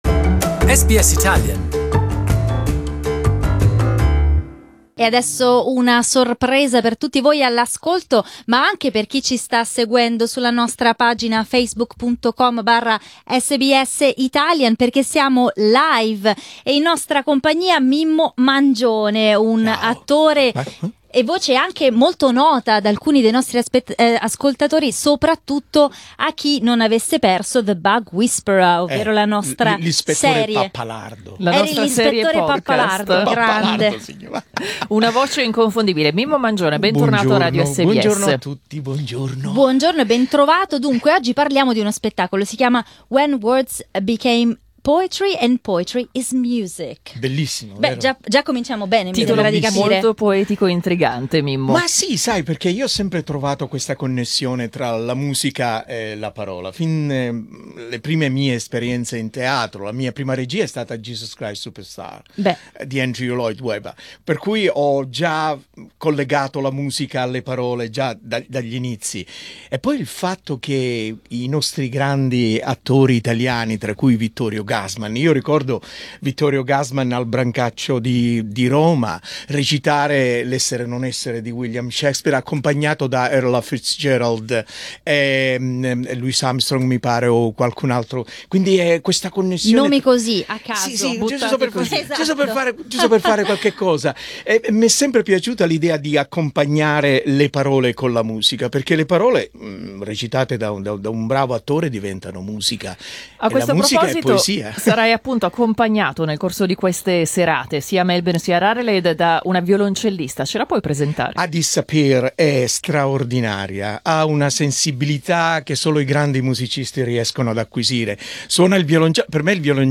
A night of monologues, poems and readings in Italian.